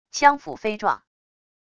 枪斧飞撞wav音频